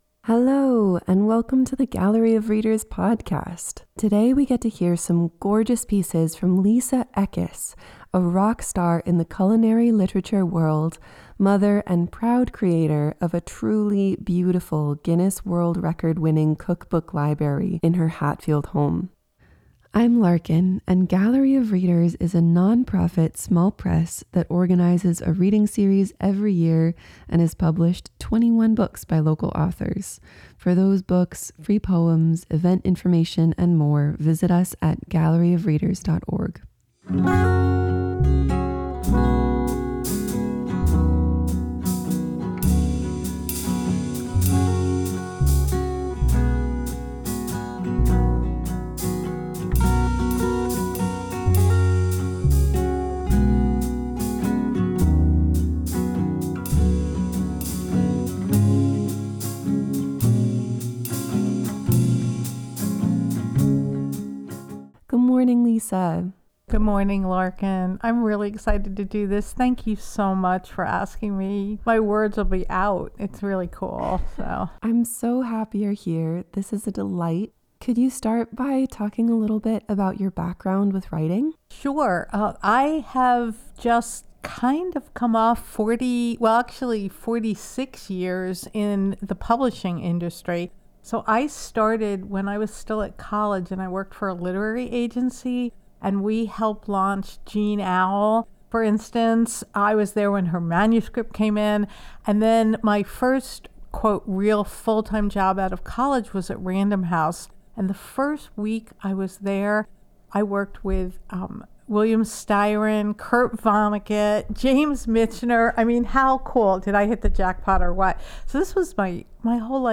reads selections of memoir and food writing.